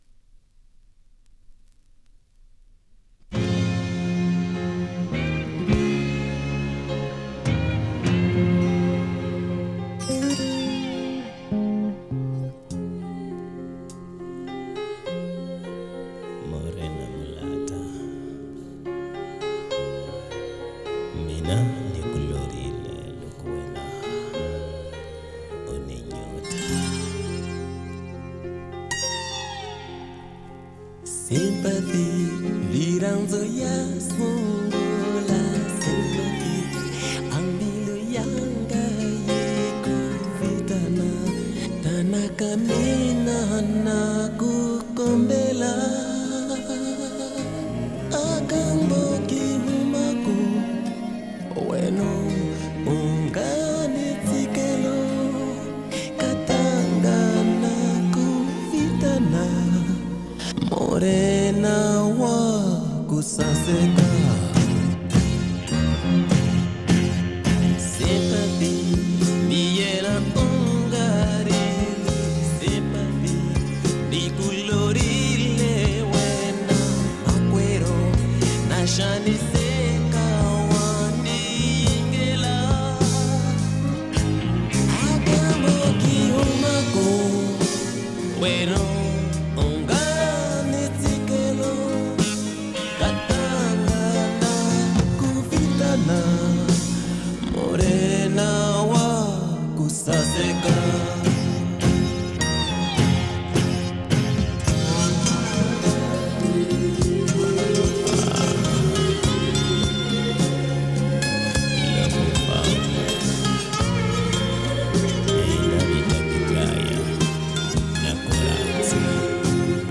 Это оригинал, был во FLACе, конвертнул в мп3.